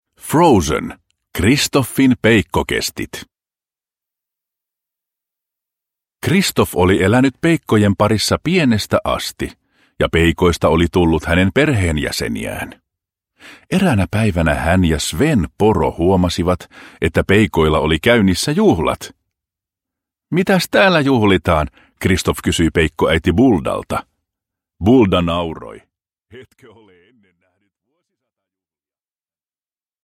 Kristoffin peikkokestit – Ljudbok – Laddas ner